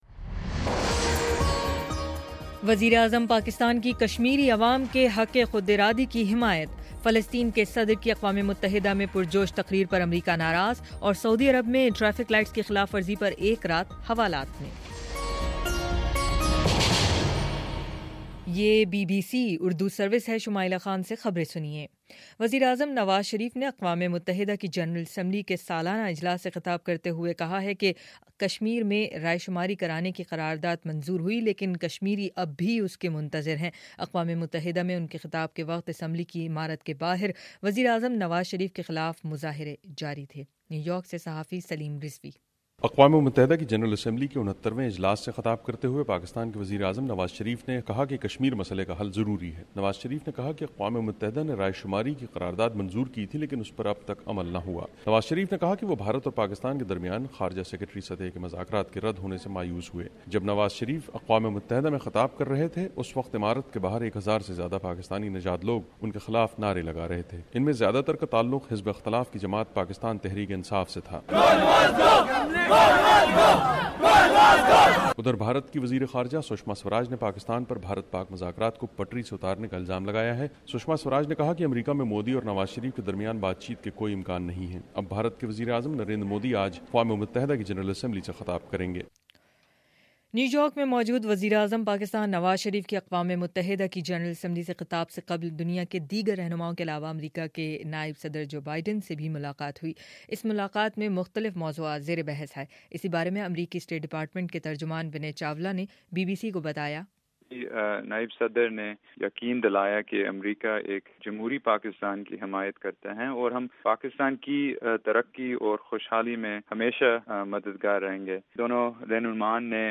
ستمبر 27: صبح نو بجے کا نیوز بُلیٹن